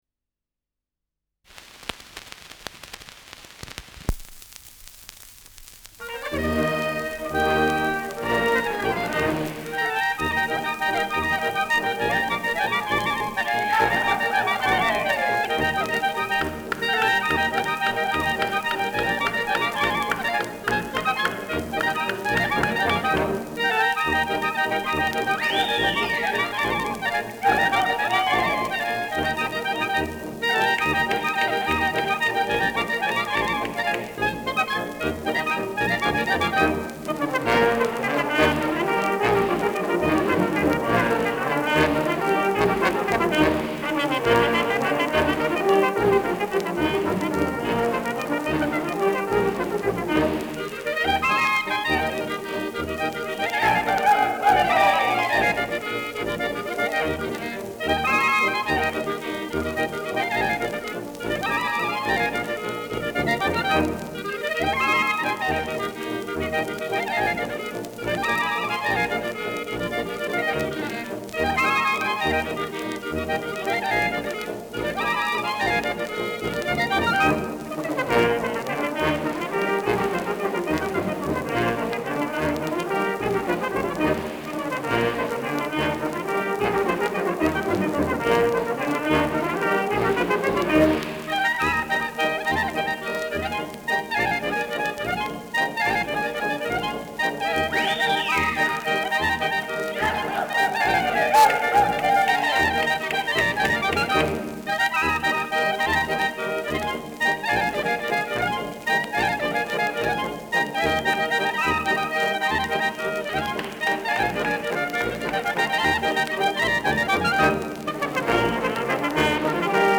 Schellackplatte
Stärkeres Grundrauschen : Gelegentlich leichtes bis stärkeres Knacken